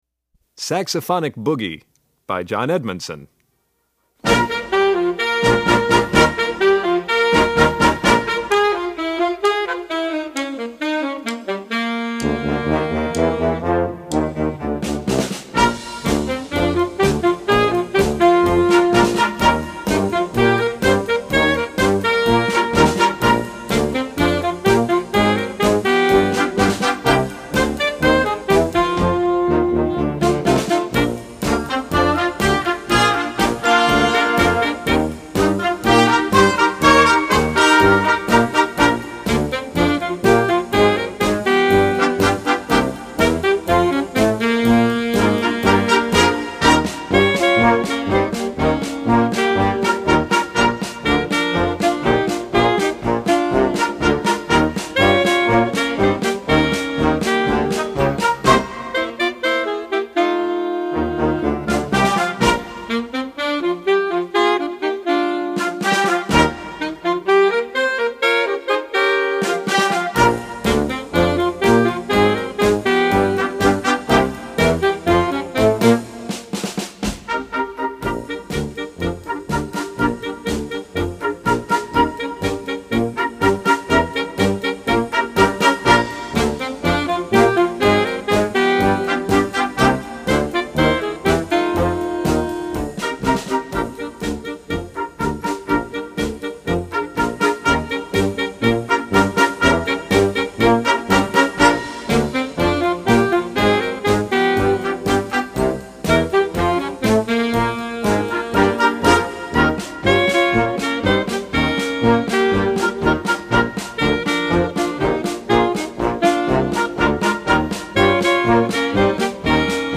Voicing: Saxophone Section w/ Band